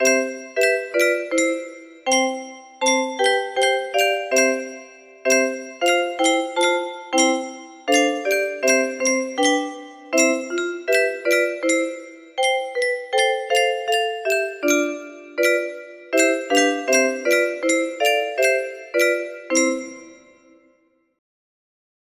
Abide with Me - 105 beats music box melody